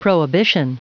Prononciation du mot prohibition en anglais (fichier audio)
Prononciation du mot : prohibition